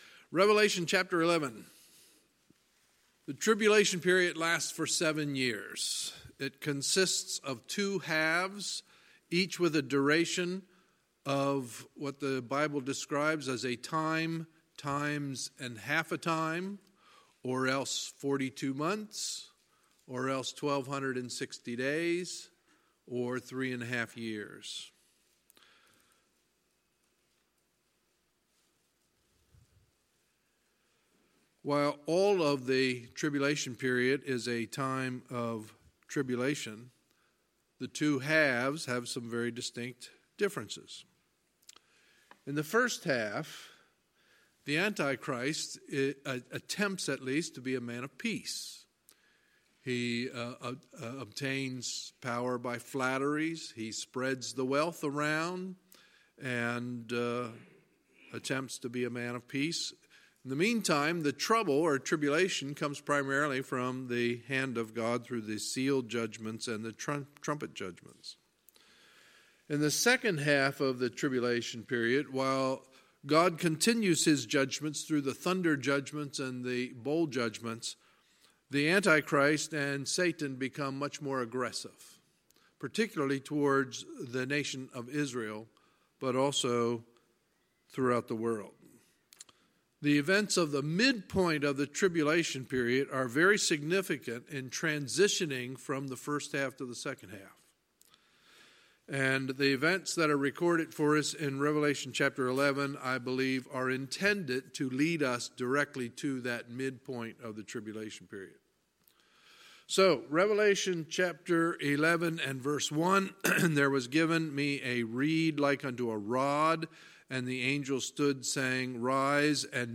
Sunday, February 24, 2019 – Sunday Evening Service
Sermons